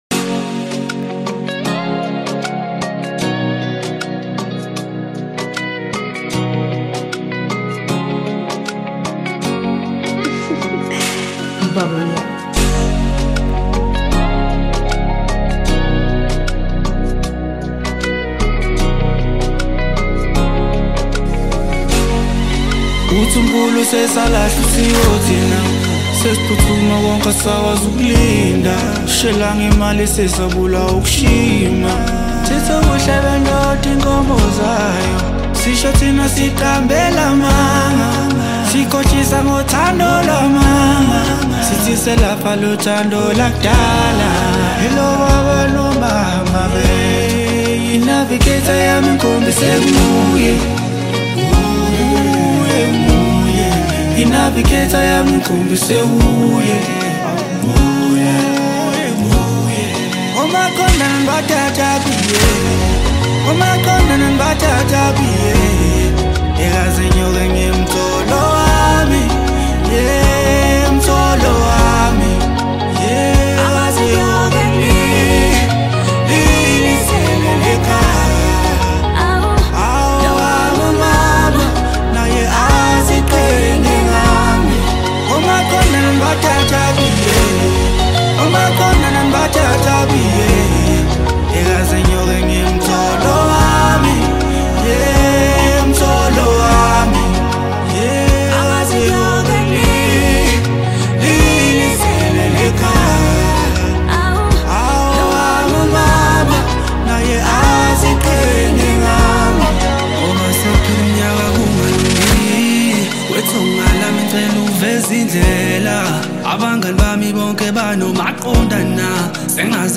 Maskandi